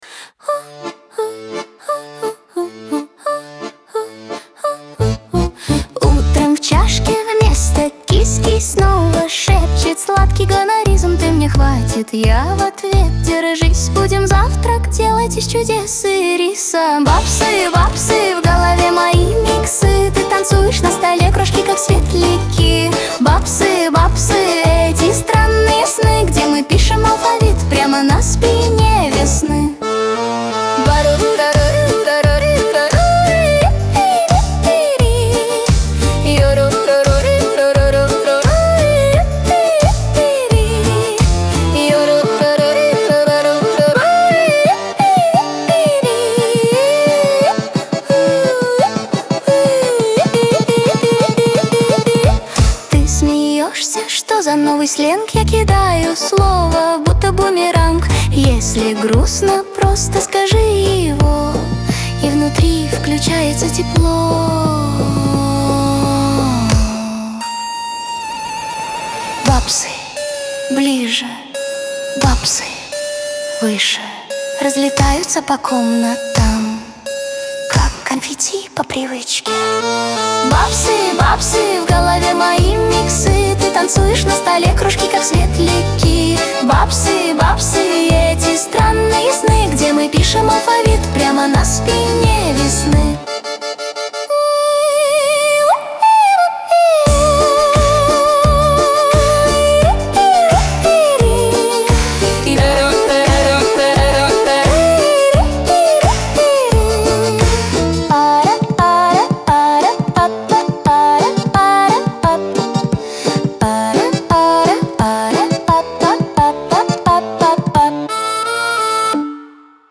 ансамбль
Лирика , Шансон